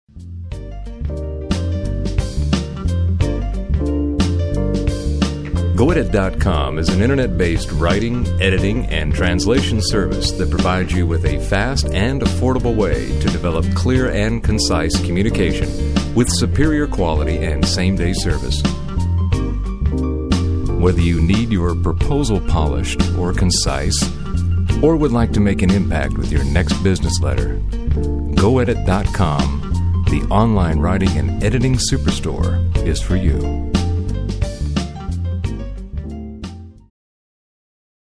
Male
Adult (30-50), Older Sound (50+)
Friendly, smooth, business like, articulate, professional, confident, conversational, calming, authoritative, movie trailer, guy next door, every man, convincing, athletic and a voice that is upbeat and animated when needed to engage the listener.
Phone Greetings / On Hold
Produced Music And Script